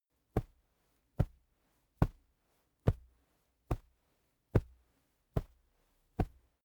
Gemafreie Sounds: Schritte